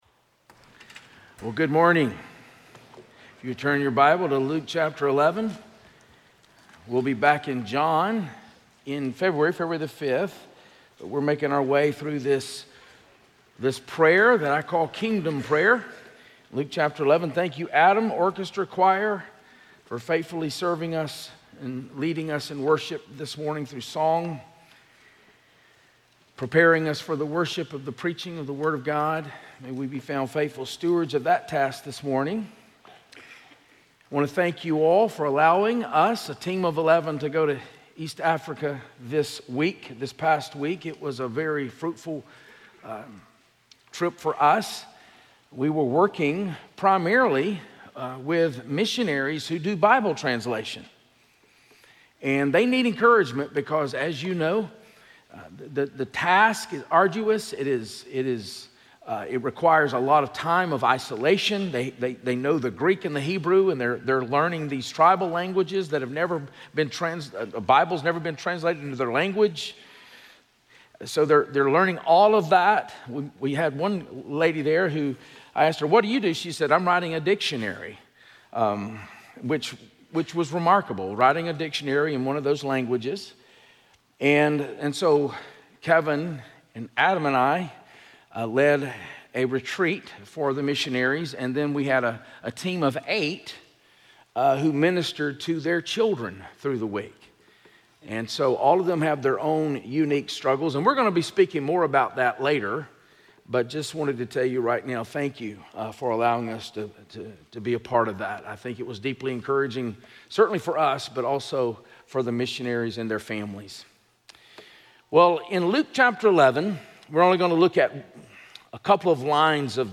Series: Stand Alone Sermons
Luke 11:2 Service Type: Sunday Morning 1